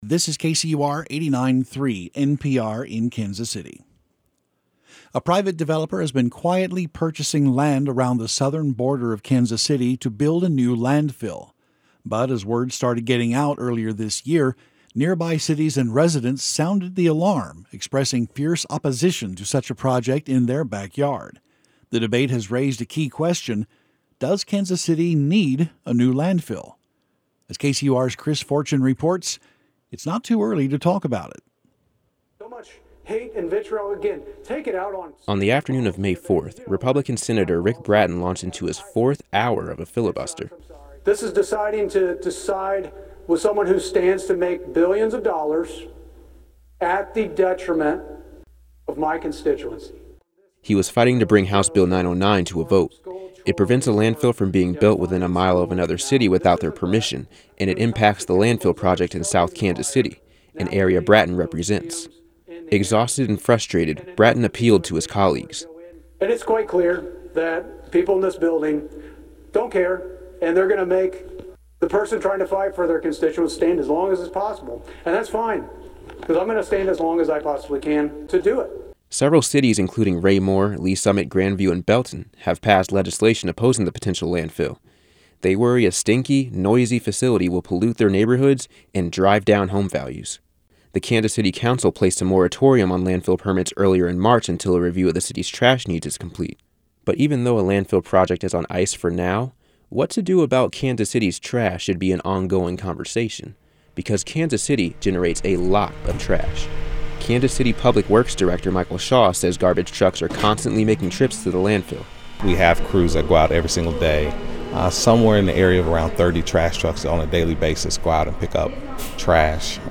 News Where will Kansas City's trash go? Locals don’t want a new landfill but we’re running out of space